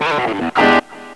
wie einst im Mai in die Saiten greift...
riff.wav